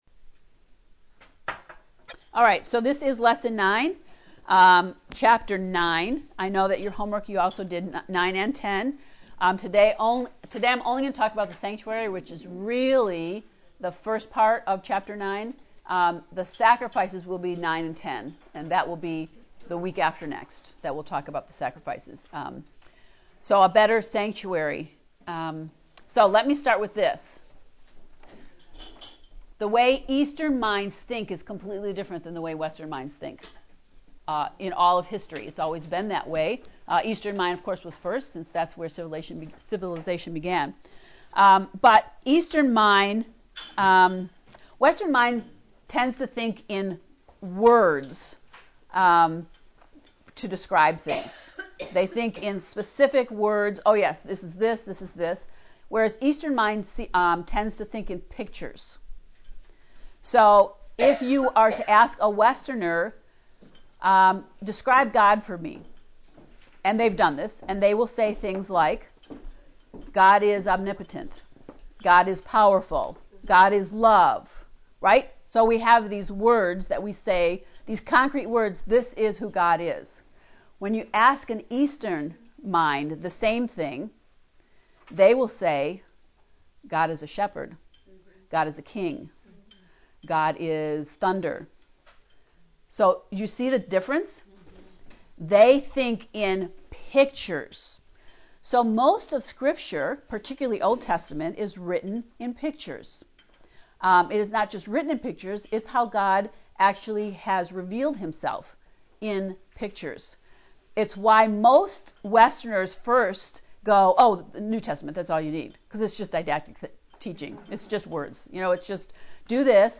To listen to the lecture on lesson 9 “A Better Sanctuary” please click below:
heb-ii-lecture-9.mp3